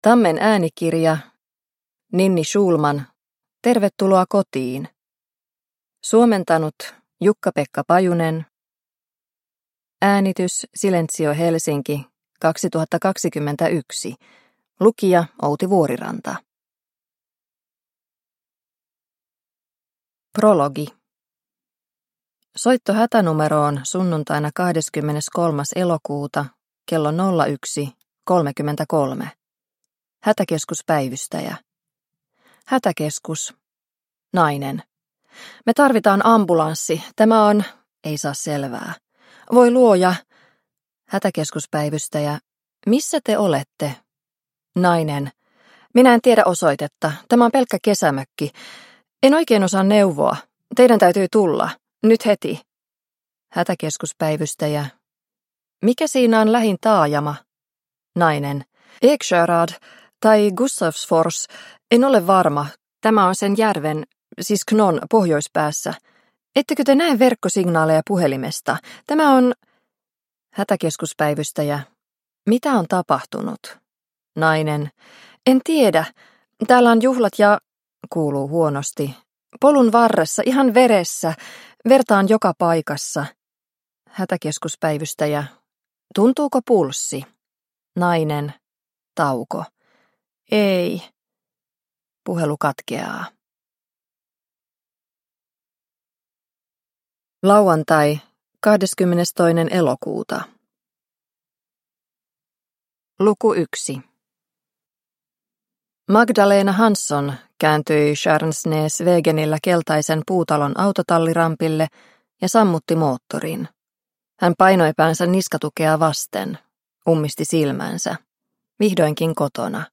Tervetuloa kotiin – Ljudbok – Laddas ner